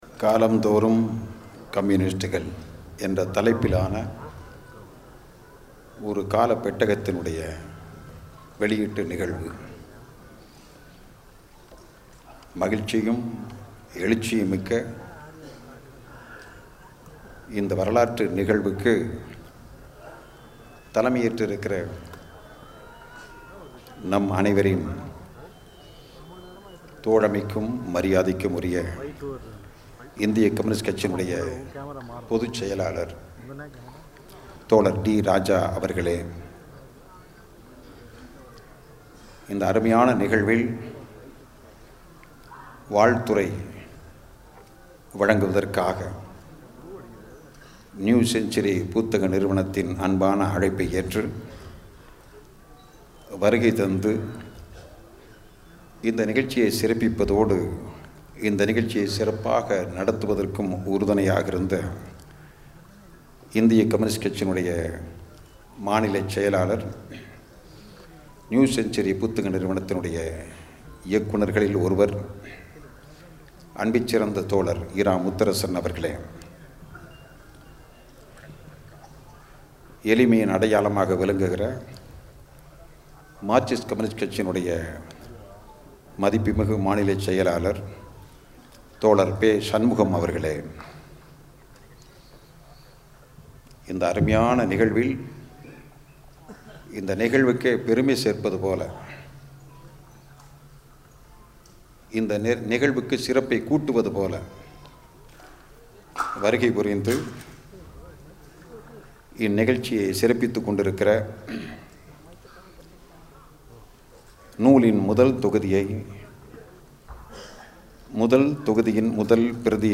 ‘காலம்தோறும் கம்யூனிஸ்டுகள்’ நூல் வெளியீட்டு விழா சென்னை காமராஜர் அரங்கில் நடைபெற்றது.